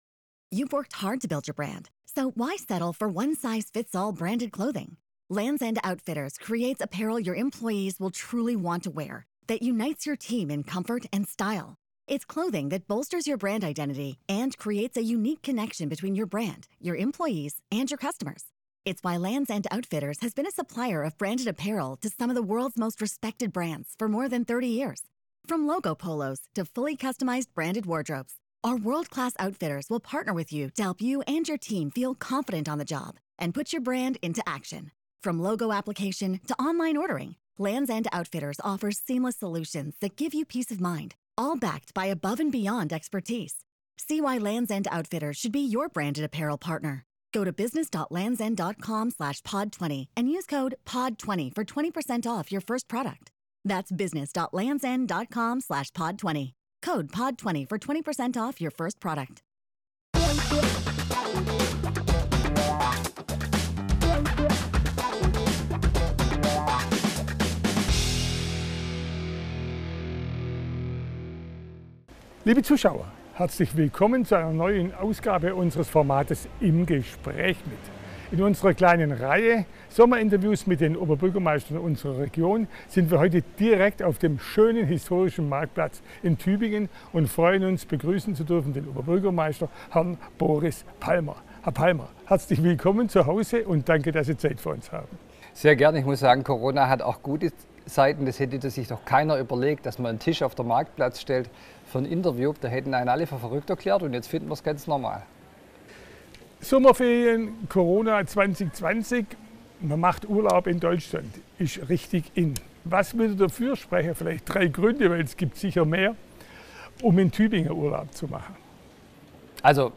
Das Sommerinterview 2020 mit Boris Palmer, Oberbürgermeister von Tübingen.